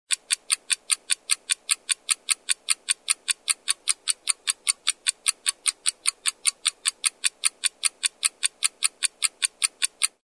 Звуки секундомера
Тиканье секундной стрелки на часах 10 секунд